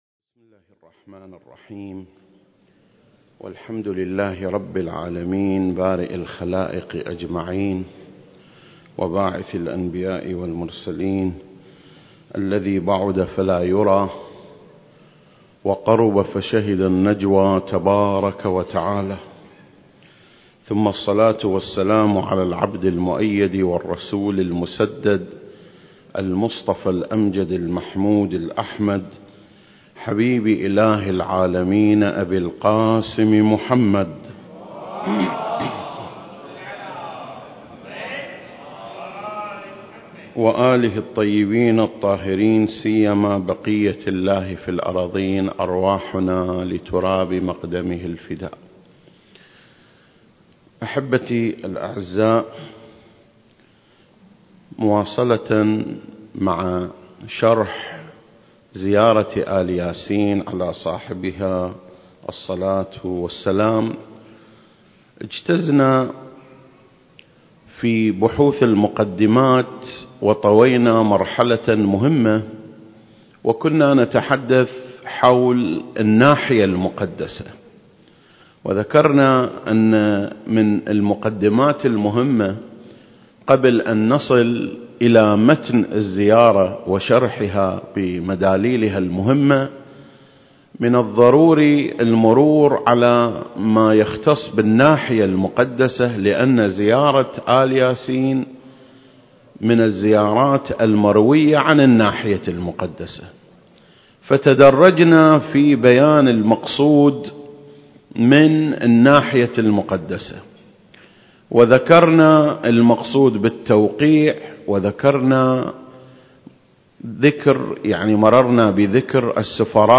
سلسلة: شرح زيارة آل ياسين (7) - الناحية المقدسة 4 المكان: مسجد مقامس - الكويت التاريخ: 2021